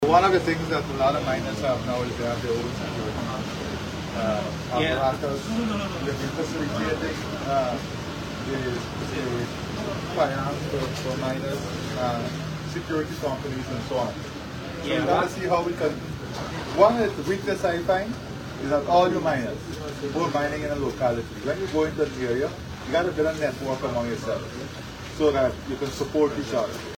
During his interaction with residents near the Bartica Stelling, President Ali committed to several interventions aimed at enhancing the community’s livelihoods.